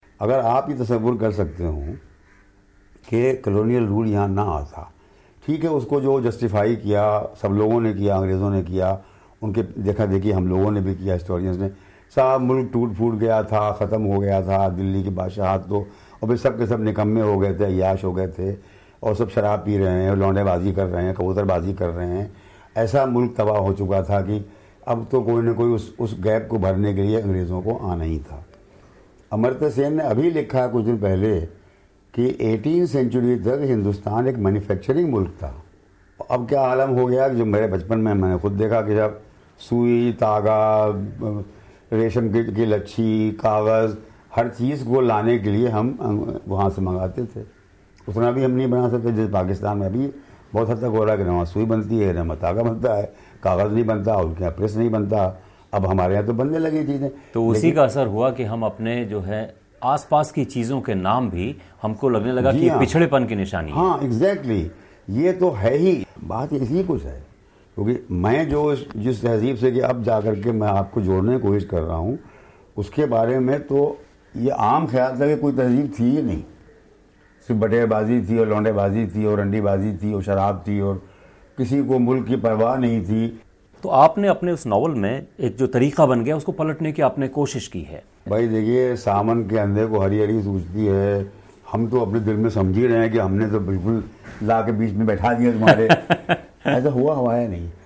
कुछ बरस पहले तक हमारी रोज़मर्रा की बातचीत का हिस्सा रहे प्याली, तश्तरी, ग़ुस्लख़ाना जैसे शब्द अब कहीं खो से गए हैं. उर्दू के मशहूर लेखक और आलोचक शम्सुर्रहमान फ़ारुख़ी बता रहे हैं कि ऐसा क्यों हुआ. पूरी बातचीत बीबीसी हिंदी रेडियो पर प्रसारित की जाएगी आज (रविवार) शाम 7:30 बजे.